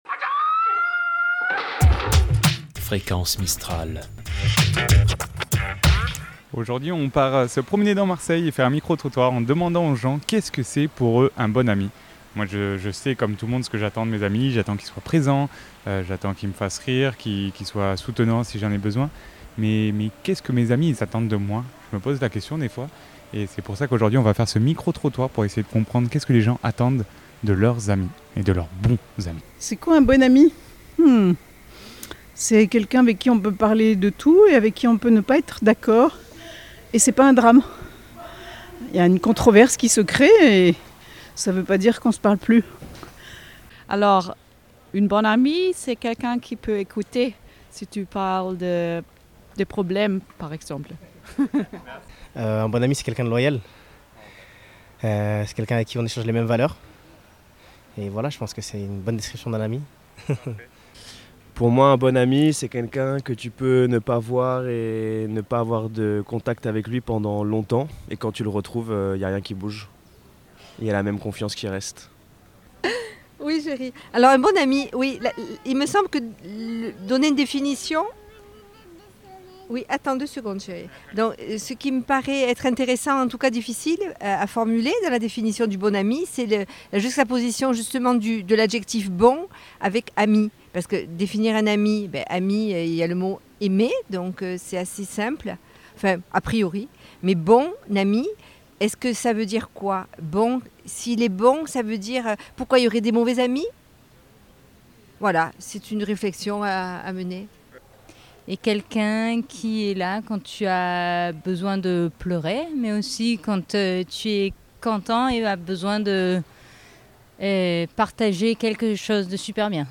Micro-trottoir - C'est quoi un bon ami?
Micro-trottoir - c'est quoi un bon ami.mp3 (3.97 Mo)
Mardi 24 Septembre 2024 Aujourd'hui, nous nous rendons à Marseille pour demander aux passants : qu'est-ce qu'un bon ami ?